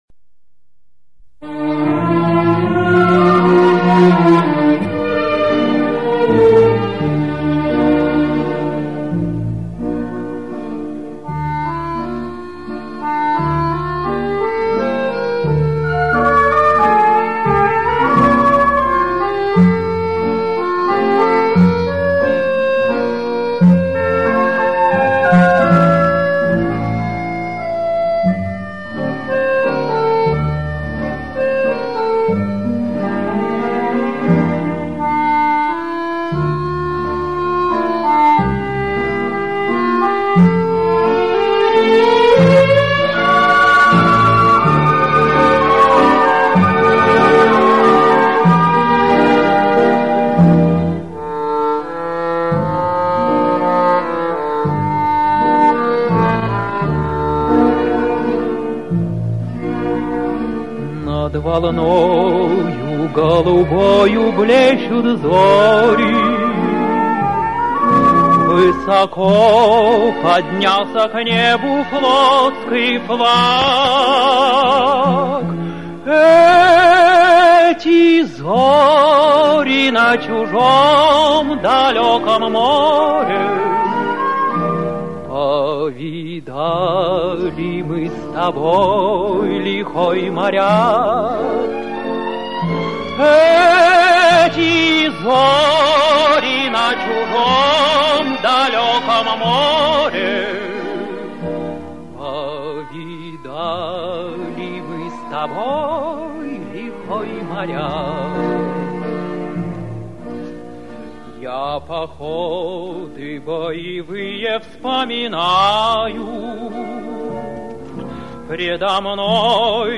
Выдающаяся лирико-патрическая песня в полном исполнении